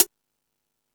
Index of /kb6/E-MU_Pro-Cussion/jazz drums
Jazz Drums(12).wav